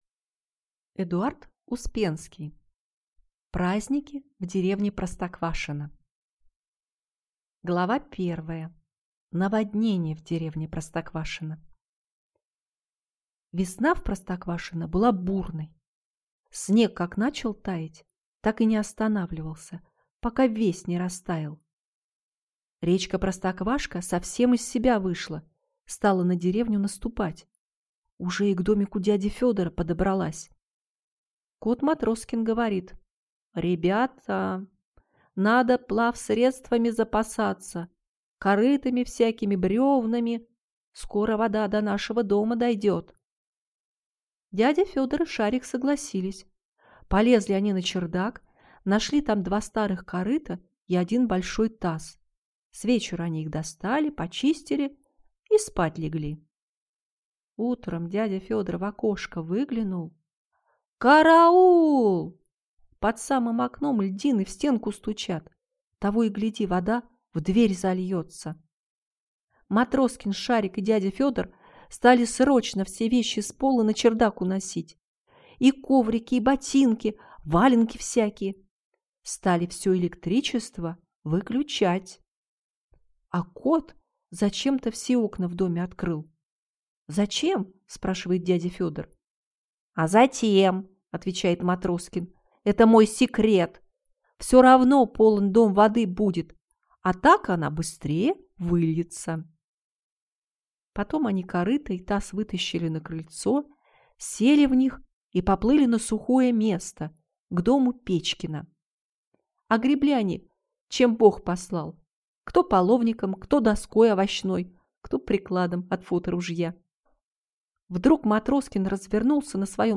Аудиокнига Праздники в деревне Простоквашино (сборник) | Библиотека аудиокниг